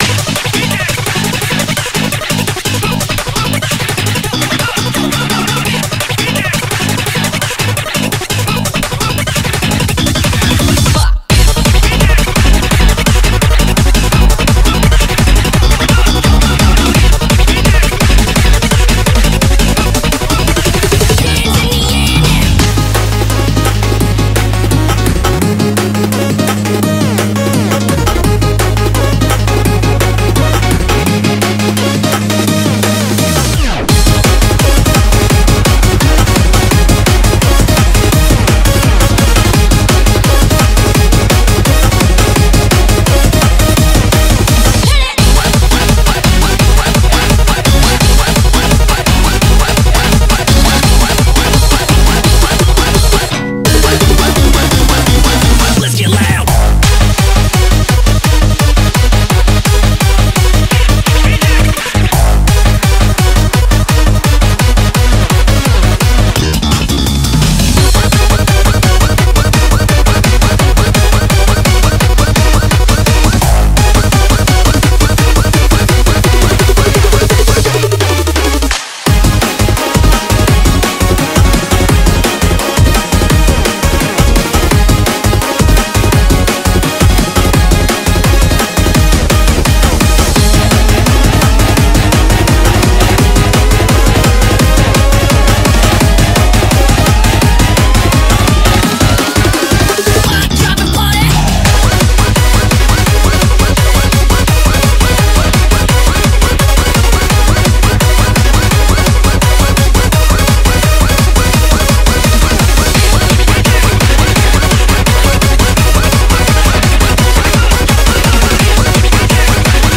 BPM170
Audio QualityPerfect (High Quality)
Commentaires[HARD TECH]